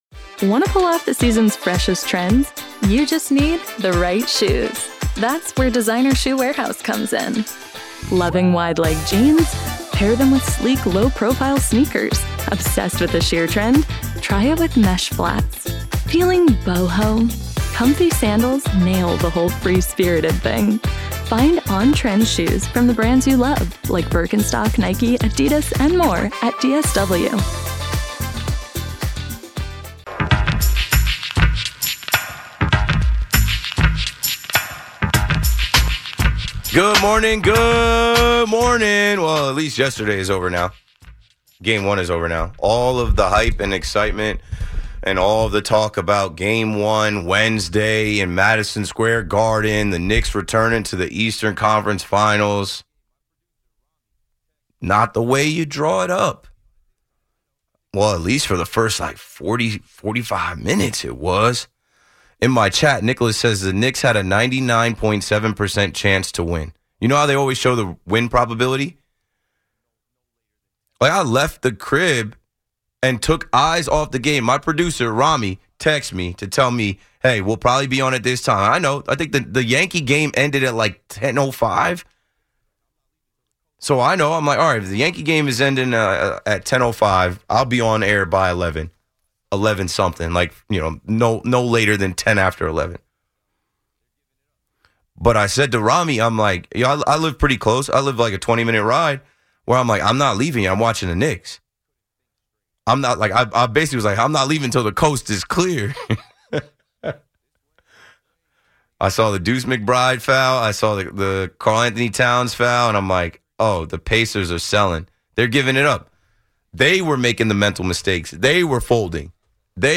Callers express their frustration after game one collapse.